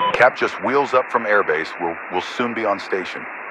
Radio-pilotNewFriendlyAircraft2.ogg